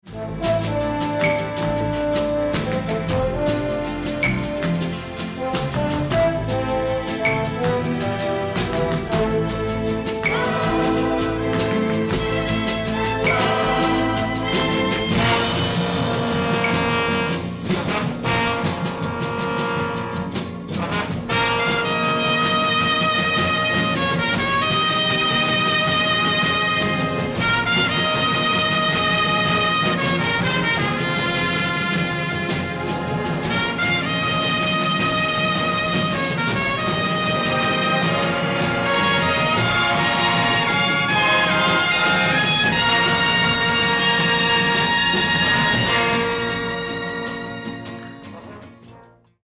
Spaghetti Western aggressive medium instr.